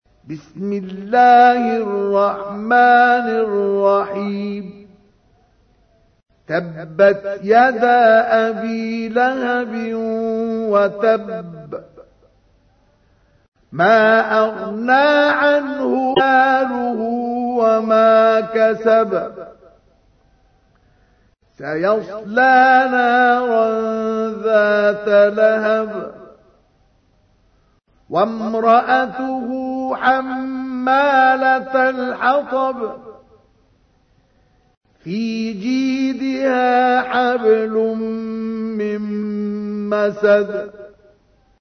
تحميل : 111. سورة المسد / القارئ مصطفى اسماعيل / القرآن الكريم / موقع يا حسين